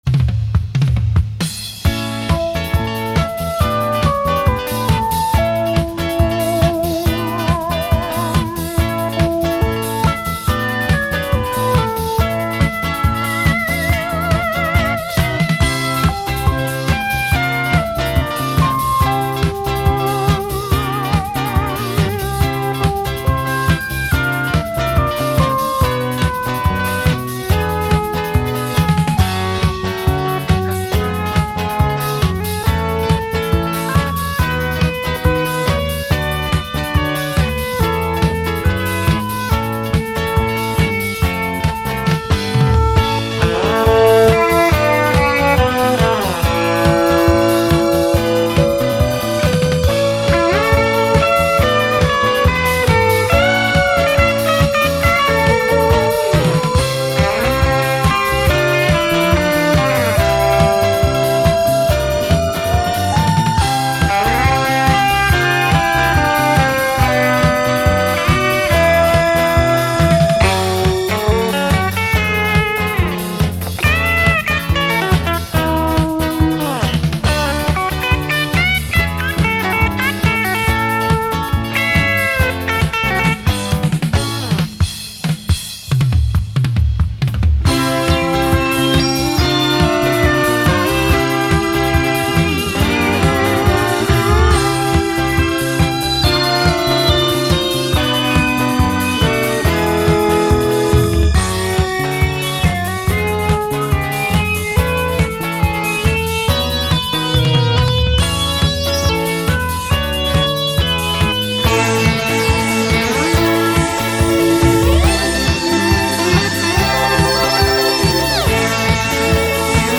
This tune also uses the same drums from above that I stole from one of my company's jingle productions.
eth-synth.mp3